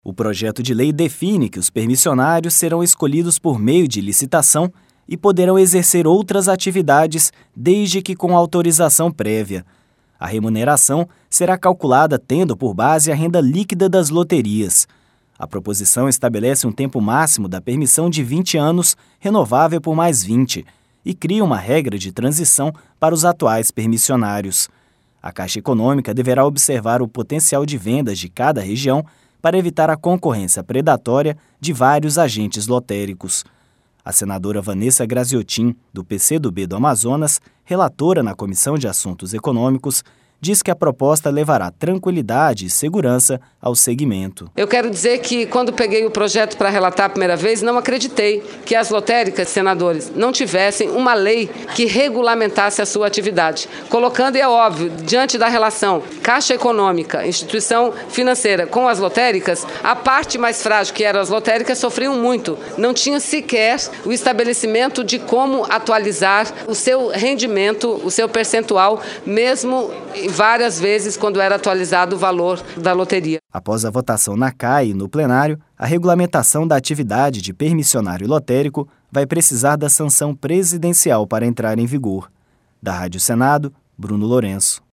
A senadora Vanessa Grazziotin, do PC do B do Amazonas, relatora na Comissão de Assuntos Econômicos, diz que a proposta levará tranquilidade e segurança ao segmento.